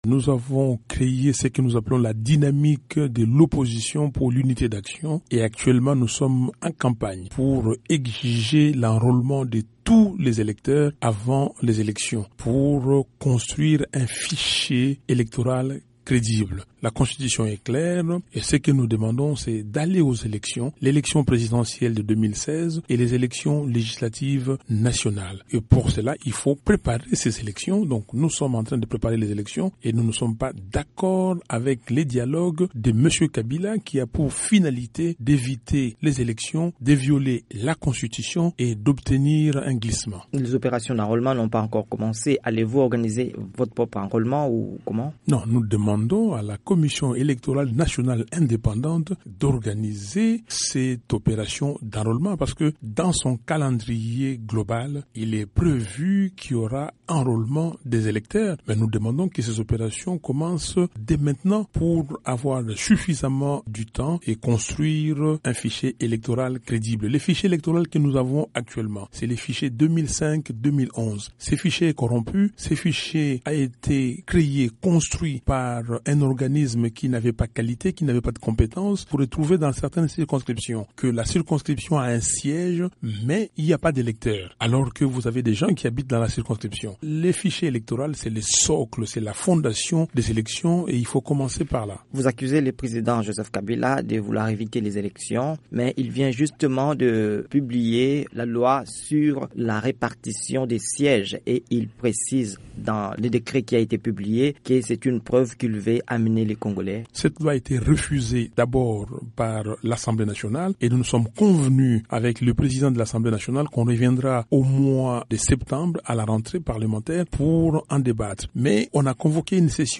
L'un d'eux, le député Martin Fayulu, était de passage à la rédaction de VOA Afrique à Washington.